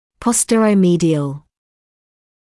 [ˌpɔstərəu’miːdɪəl][ˌпостэроуэн’миːдиэл]заднемедиальный